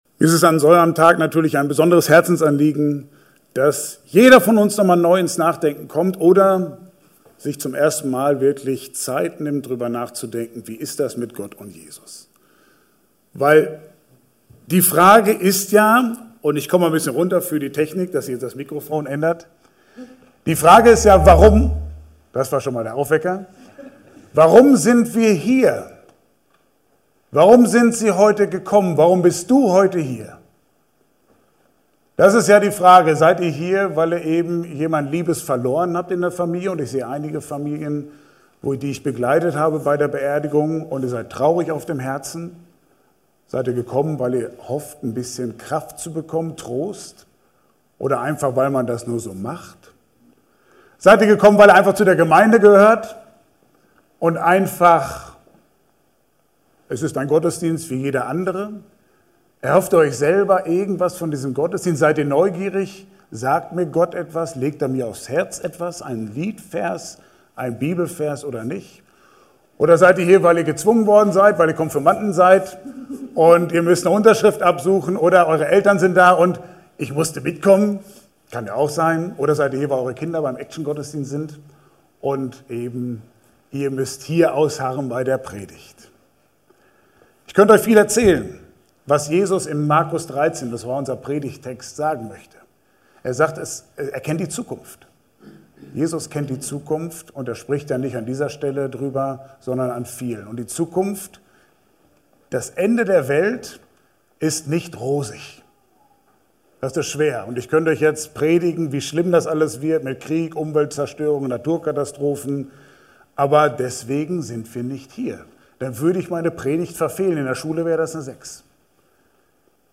28-37 Dienstart: Gottesdienst « Immer wieder